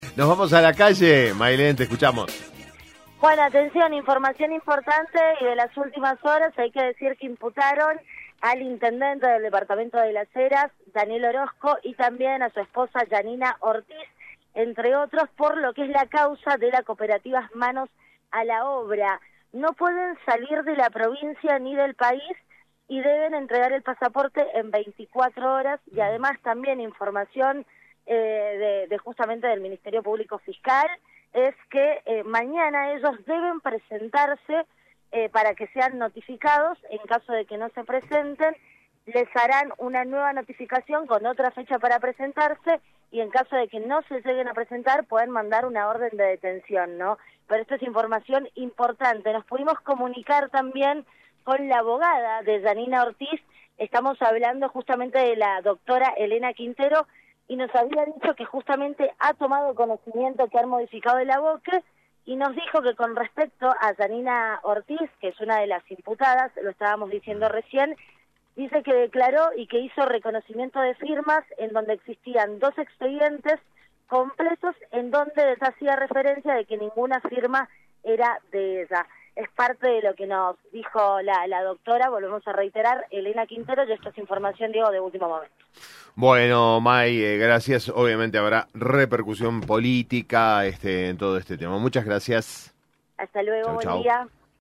Móvil de LVDiez desde Polo Judicial